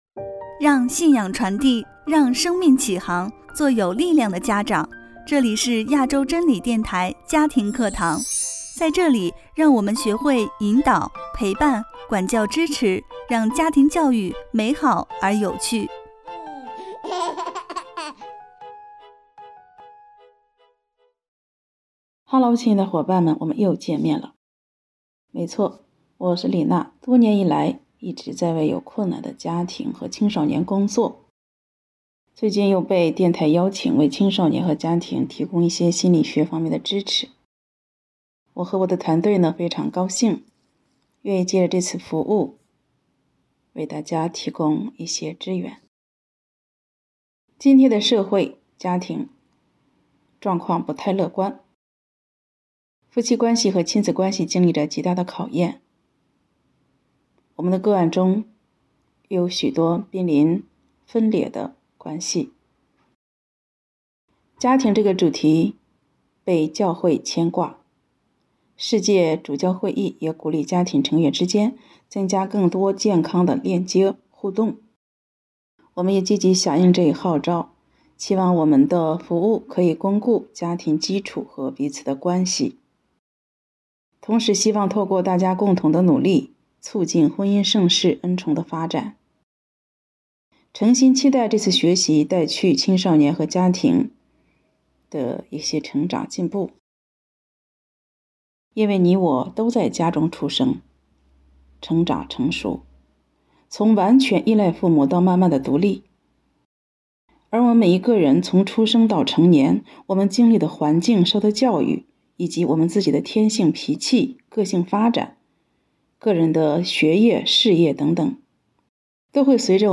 【家庭课堂Ⅳ】|第一讲|婴儿期的社会性及人格